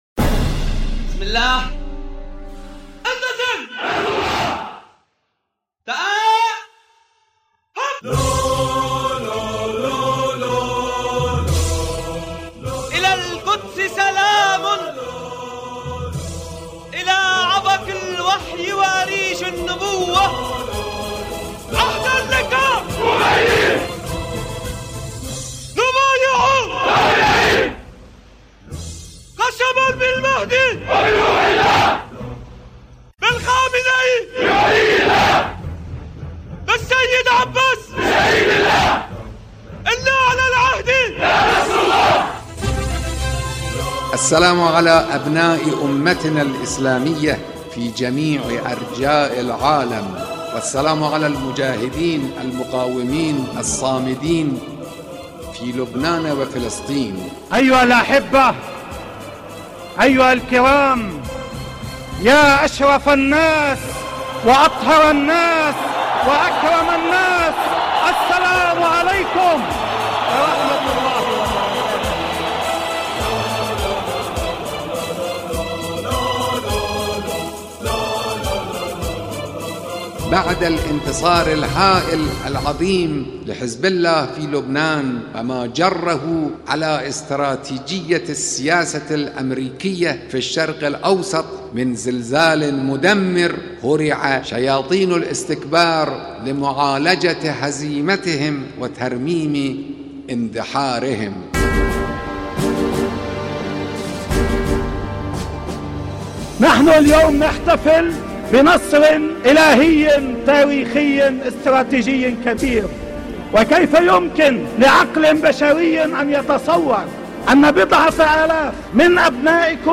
آنچه شما در این قطعه‌ی صوتی می‌شنوید، نکاتی است به روایت ولی‌امر مسلمین حضرت آیت‌الله خامنه‌ای و سیدحسن نصرالله دبیرکل حزب‌الله لبنان به زبان عربی ، درباره‌ی پیروزی مقاومت اسلامی در جنگ 33 روزه در برابر رژیم صهیونیستی. بخش‌های ابتدایی این پادکست، با اهدای سلام از سوی حضرت آیت‌الله خامنه‌ای و سیدحسن نصرالله به رزمندگان مقاومت و ملت لبنان آغاز می‌شود و پس از برشمردن آثار پیروزی حزب‌الله بر استراتژی آمریکا در خاورمیانه، تلاش رژیم صهیونیستی برای صدمه به حزب‌الله، و لزوم انتقال «تجربه‌ی مقاومت» به ملت‌های جهان، با تلاوت آیه‌ی شریفه‌ای از کلام‌الله مجید درباره‌ی وعده‌ی پیروزی نهایی به پایان می‌رسد.